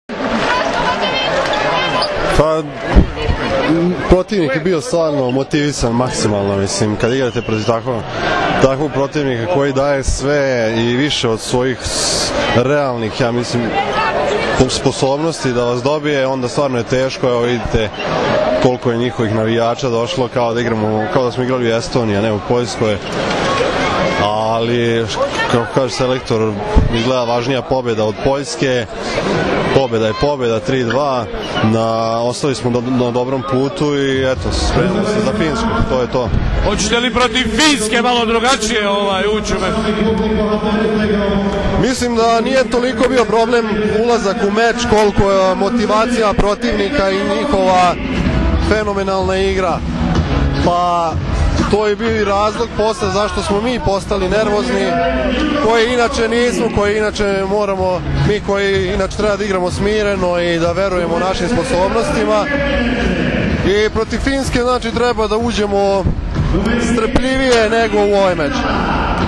IZJAVA DRAŽENA LUBURIĆA